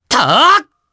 Arch-Vox_Attack4_jp.wav